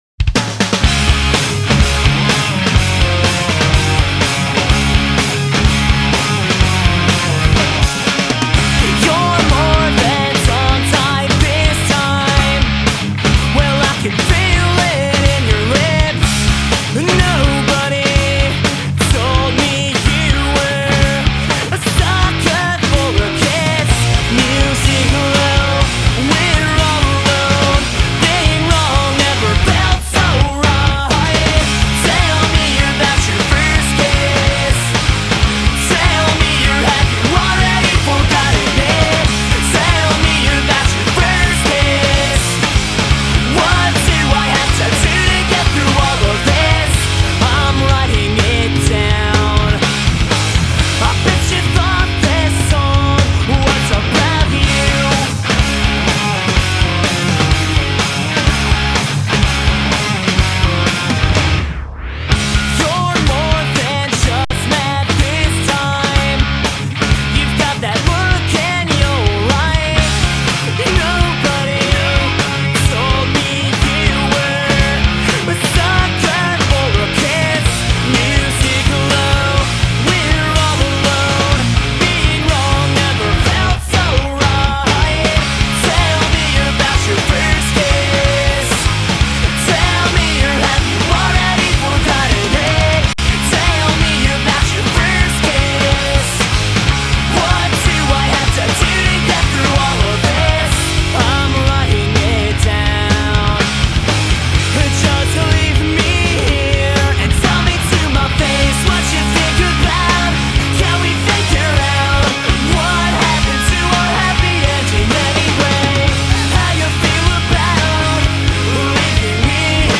You'll hear the song break and then what sound like some kind of tape rewind and Fast forward. maybe an old tape machine? does anyone know where I could get a sound effect like this or how I could achieve this...